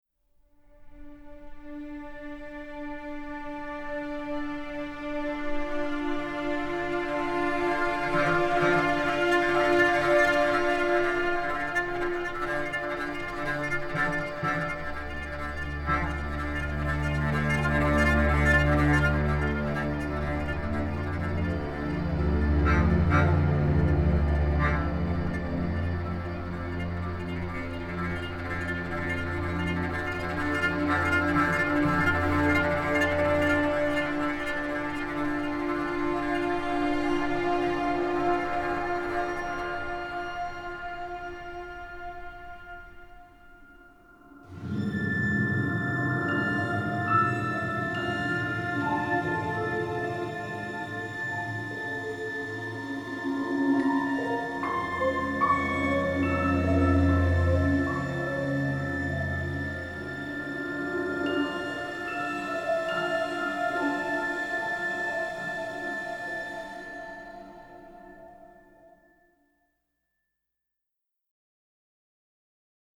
Genre : Soundtrack